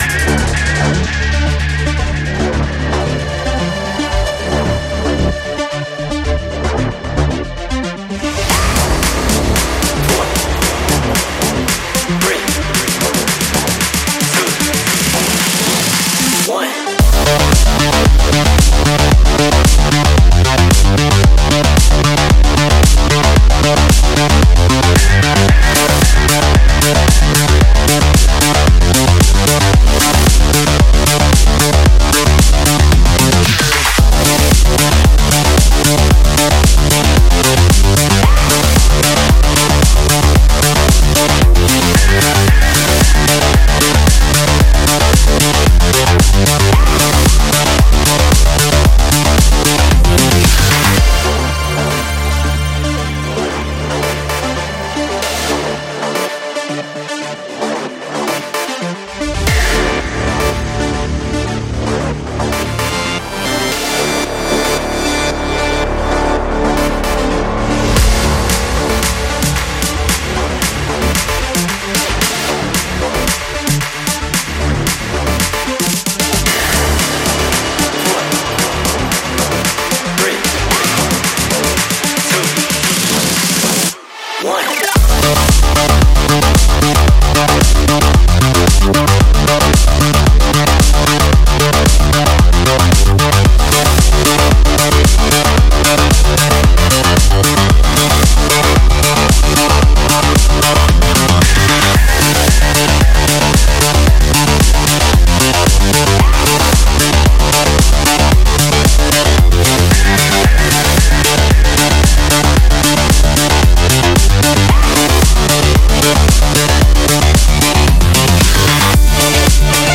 Sport Racing Electro